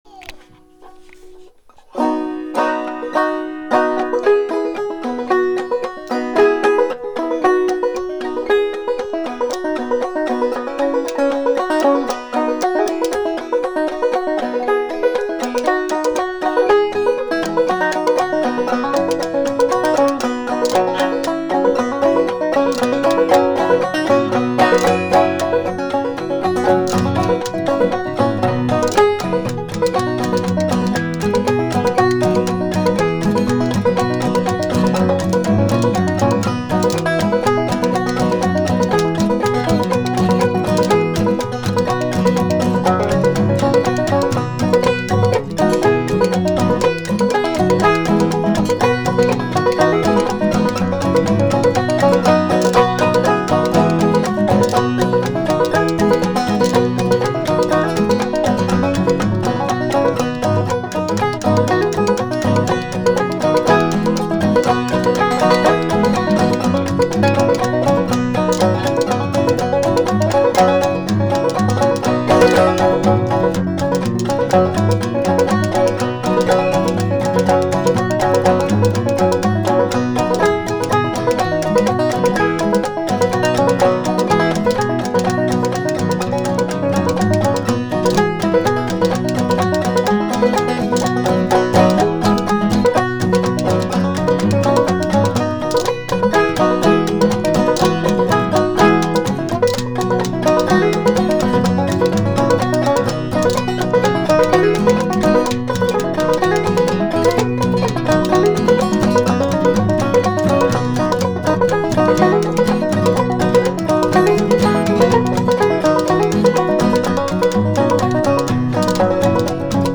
The recordings are all quite amateur, and most are, what I would refer to as, sketches, as opposed to finished and refined pieces of music.
Most are instrumental guitar, some are banjo and a few other instruments that I play with enough skill to record with them.